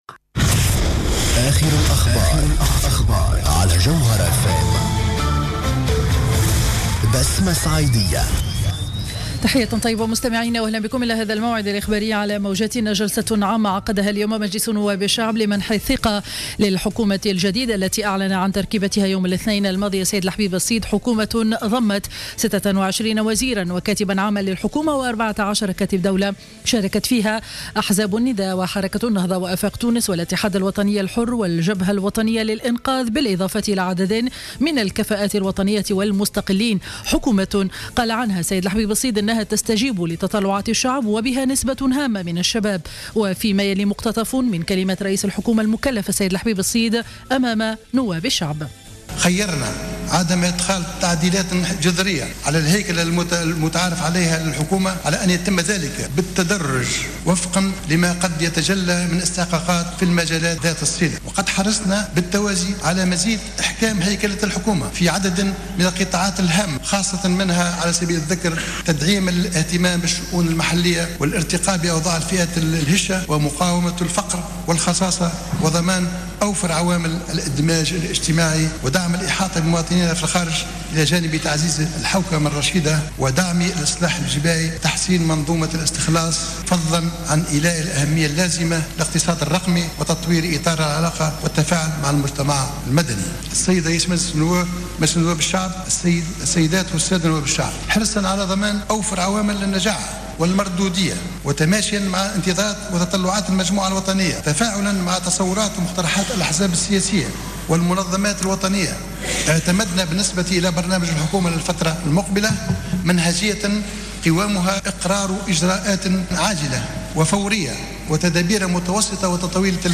نشرة أخبار منتصف النهار ليوم الاربعاء 04-02-15